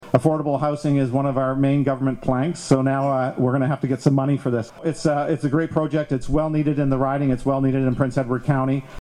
Bay of Quinte Neil Ellis speaks at the key exchange ceremony at the former Pinecrest Memorial Elementary School in Bloomfield on October 5, 2020.